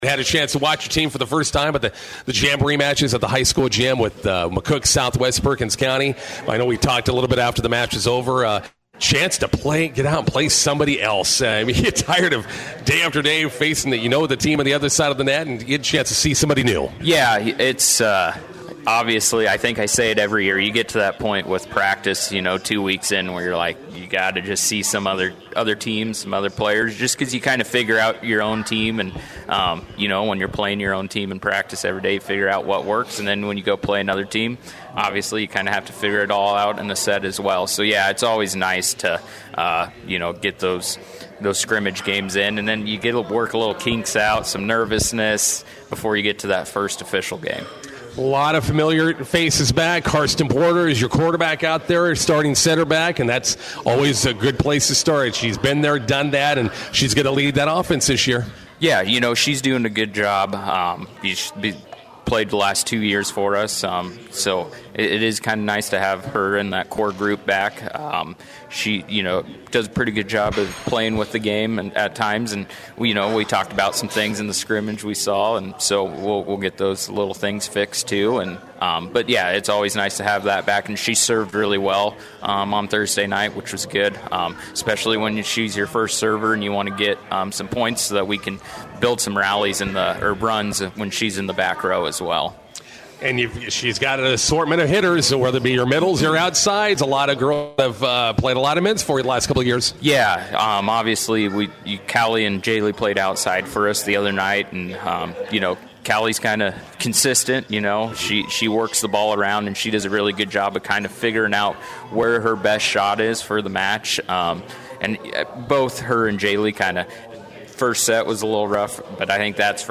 INTERVIEW: Bison volleyball starts tonight in Lexington.